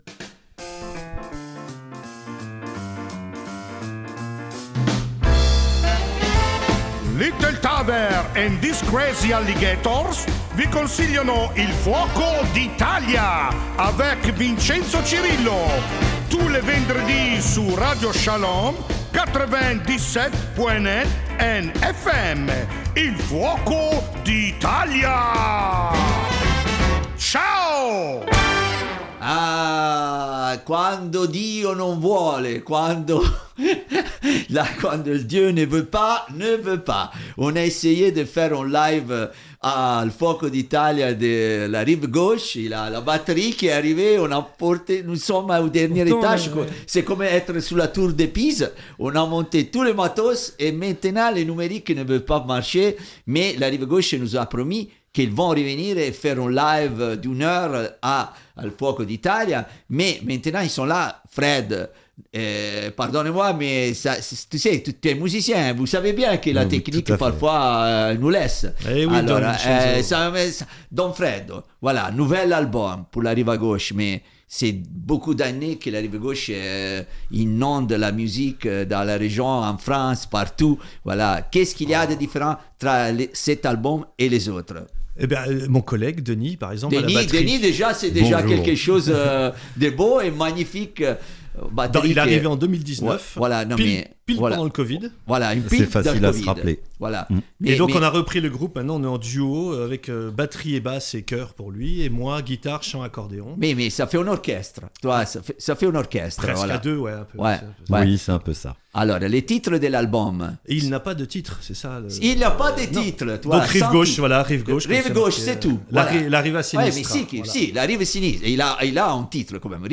Aujoud'hui, pas de live comme prévu mais un super moment passé avec nos amis pour discuter de leur nouvel album tout frais tout beau tout neuf =)